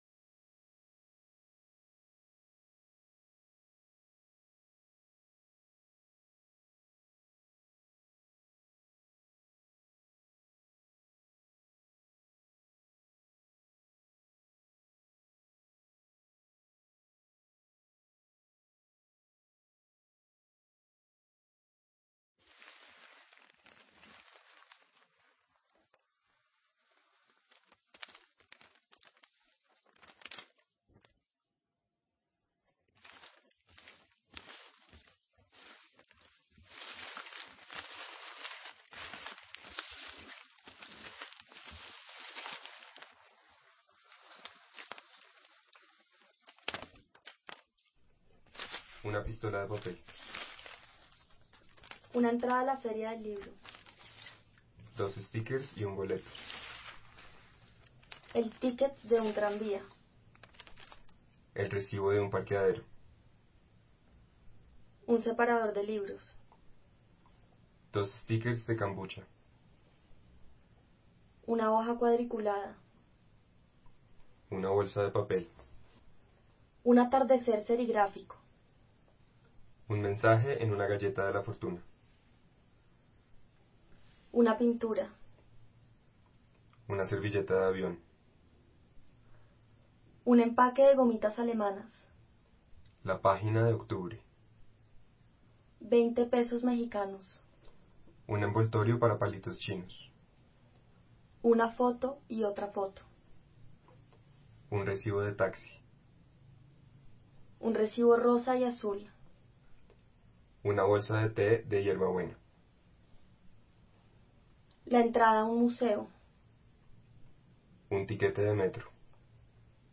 Luego, en una segunda instancia, el espacio se convierte en una sala vacía, en un escenario, en el que una locución junto con 2 libros construyen de manera cíclica y repetitiva las historias mencionadas anteriormente.